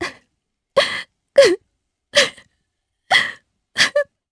Seria-Vox_Sad_jp.wav